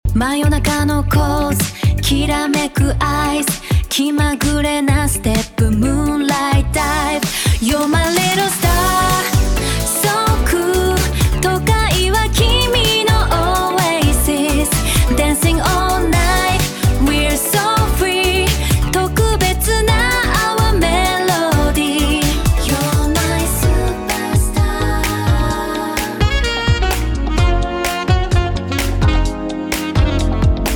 生成した音楽のサンプルがこちら。
サンプルミュージック① (女性ボーカル)